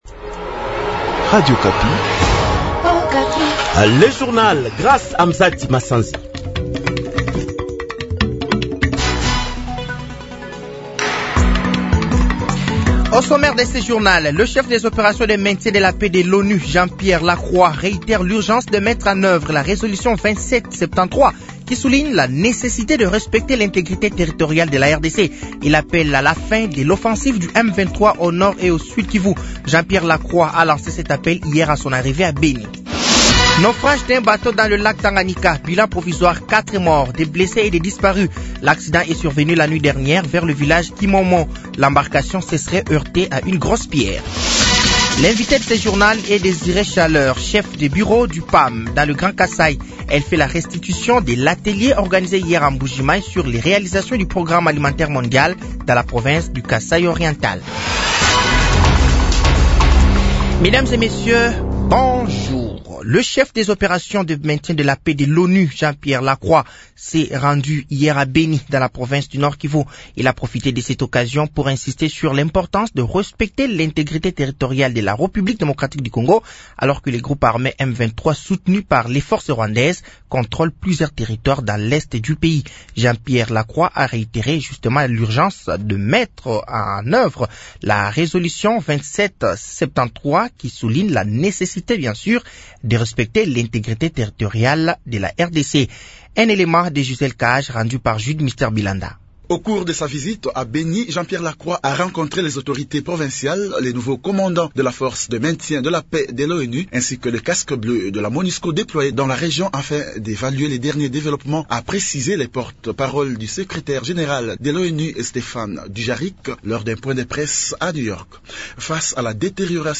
Journal de 15h
Journal français de 15h de ce samedi 01 mars 2025